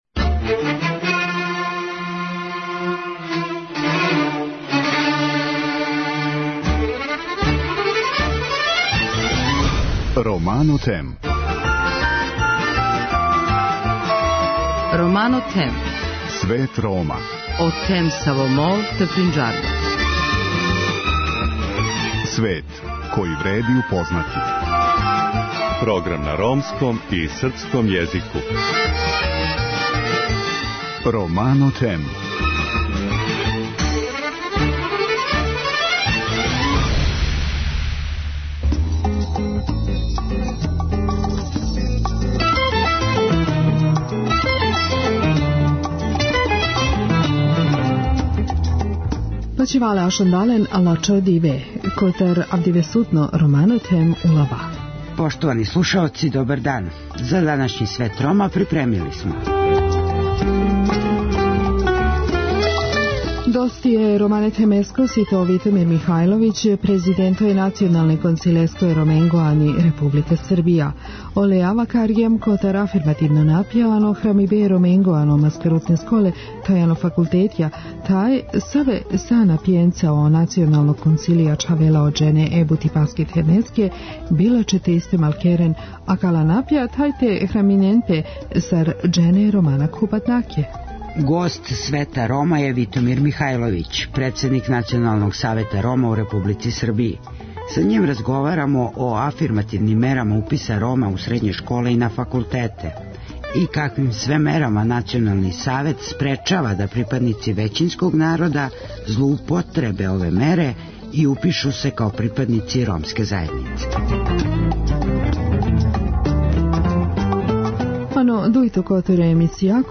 Гост Света Рома је Витомир Михајловић, председник Националног Савета Рома у Републици Србији. Са њим разговарамо о афирмативним мерама уписа Рома у средње школе и на факултете и каквим све мерама Национални Савет српечава да припадници већинског народа злоупотребе ове мере и упишу се као припадници ромске заједнице.